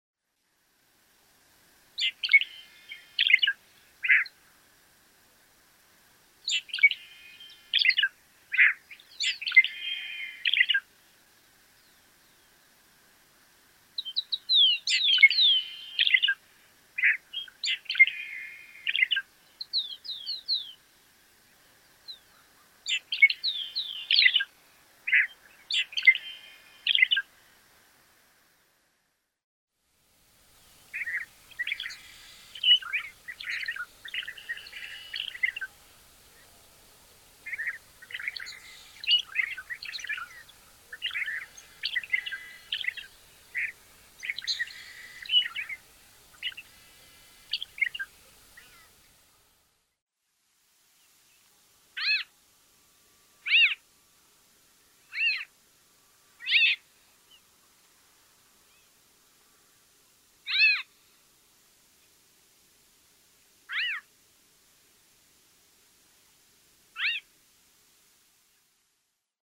Phygilus fruticeti - Yal negro
Phrygilus fruticeti.mp3